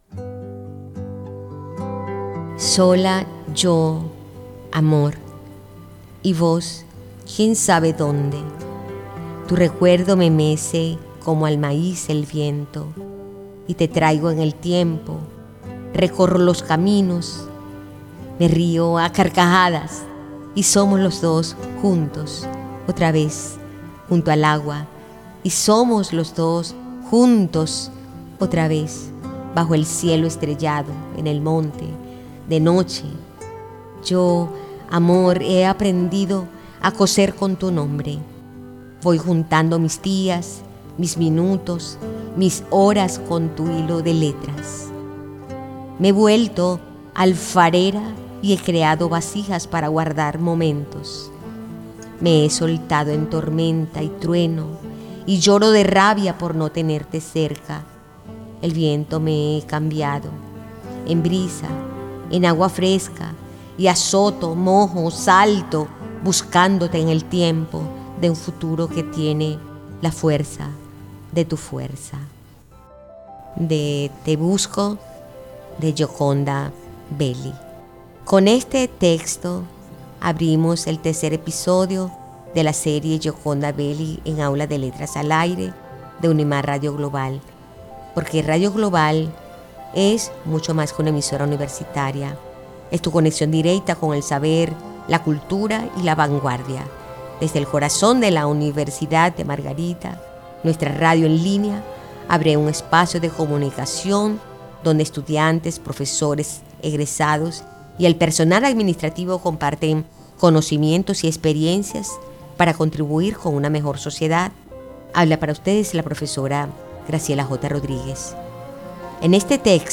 Aula de Letras al Aire es un programa de radio universitaria que lleva la literatura (poemas y relatos) más allá del aula, ofreciendo una experiencia auditiva y accesible para la comunidad universitaria y el público en general.